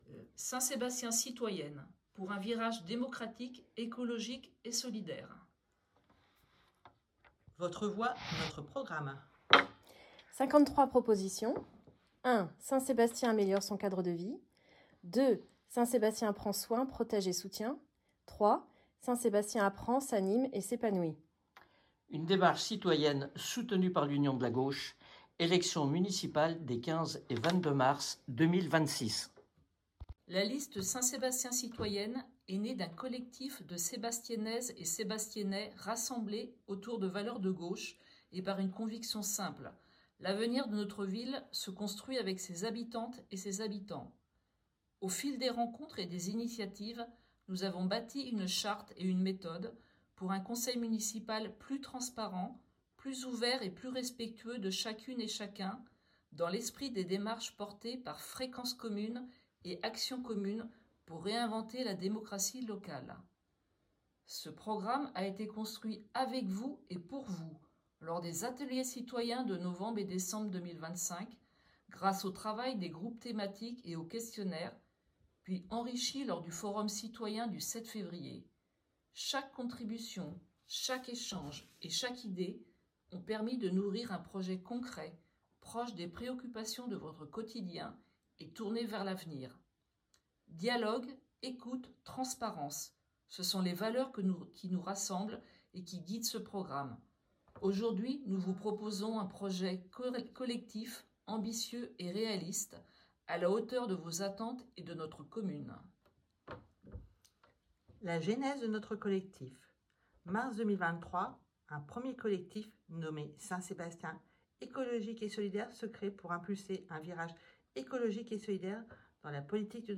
lecture_programme.mp3